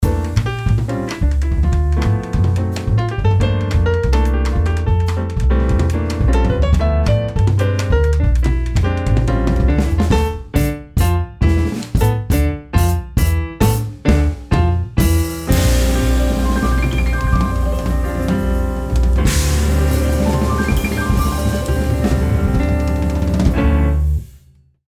My studio is designed to work as a rehearsal studio, giving your group access to the equipment needed to make recordings to be used for EPK’s, demos, releases, etc. It works well for anyone wanting to have accurate representation of their sound to be used for social media and marketing.
Jazz Trio
Jazz-Trio.mp3